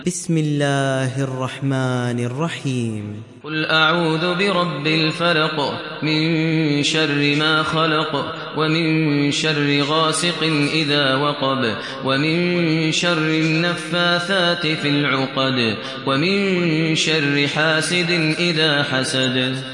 دانلود سوره الفلق mp3 ماهر المعيقلي روایت حفص از عاصم, قرآن را دانلود کنید و گوش کن mp3 ، لینک مستقیم کامل